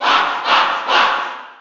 File:Fox Cheer NTSC SSB4.ogg
Fox_Cheer_NTSC_SSB4.ogg.mp3